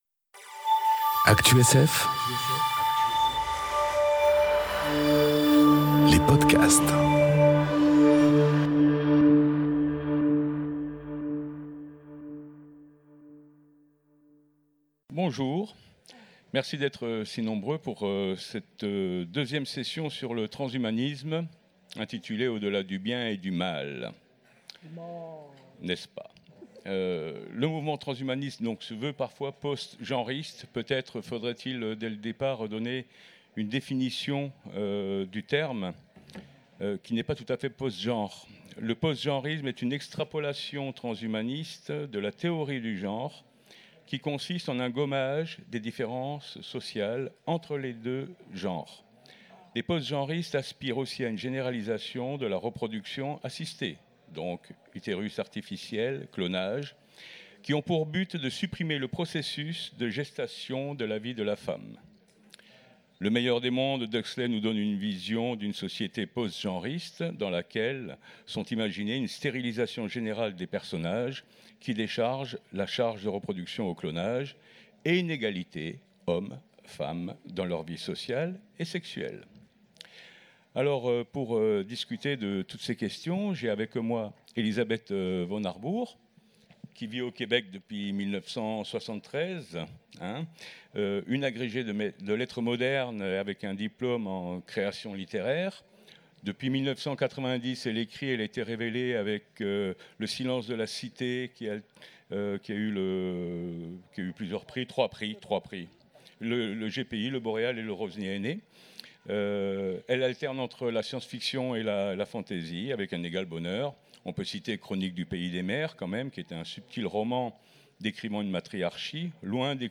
Conférence Transhumanisme 2 : Au-delà du bien et du mâle enregistrée aux Utopiales 2018